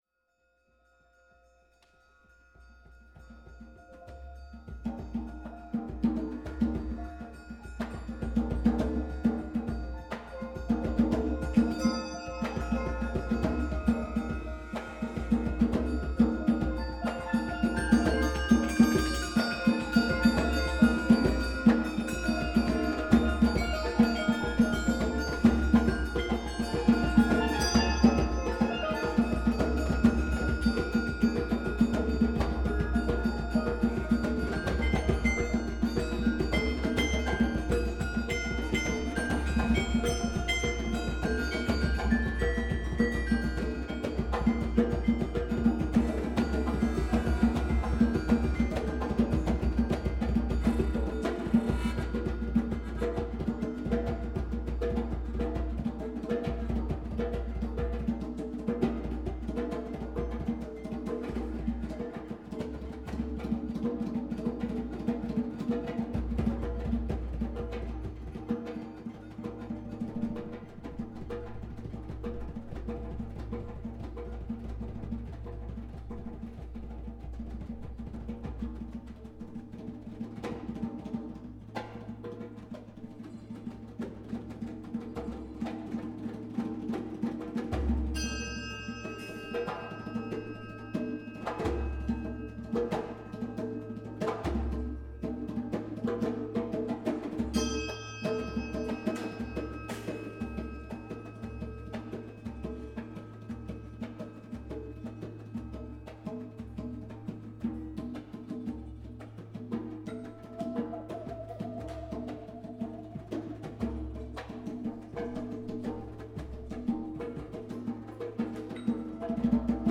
a live recording
recorded live in the hall